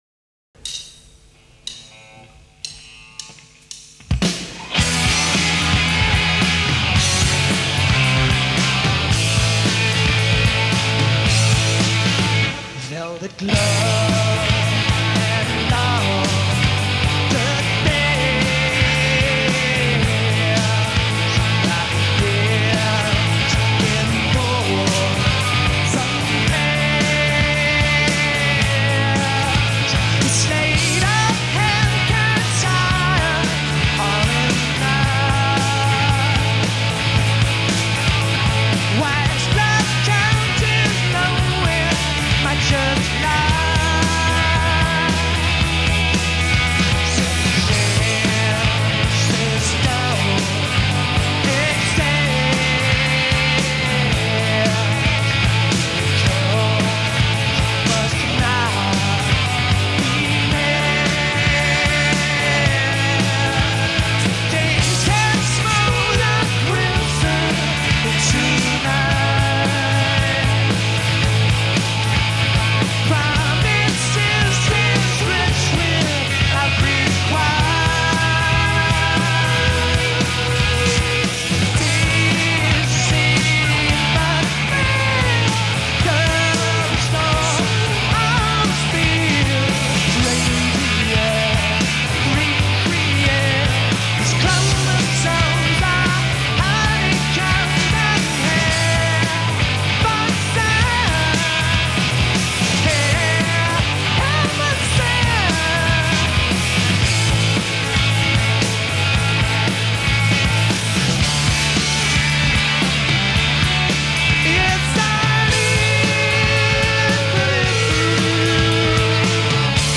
enregistrée le 08/06/1993  au Studio 105